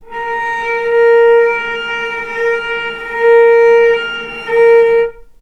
vc_sp-A#4-mf.AIF